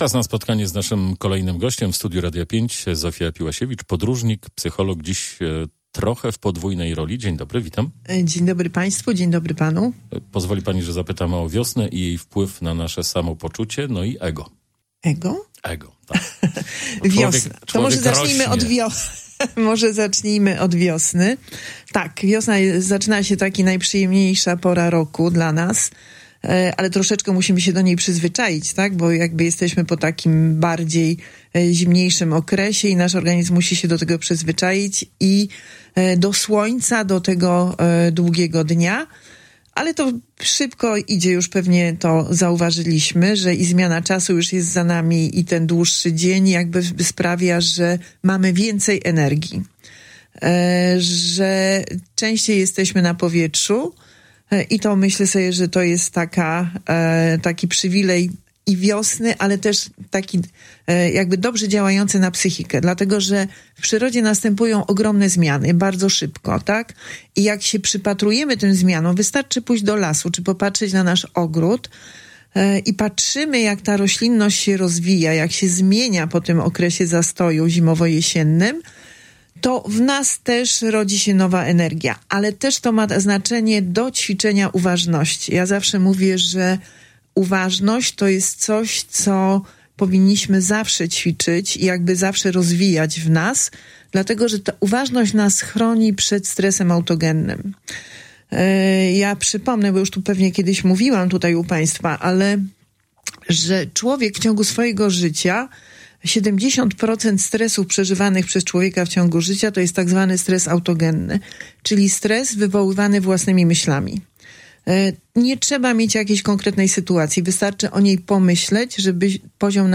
Poniżej pełne nagranie rozmowy: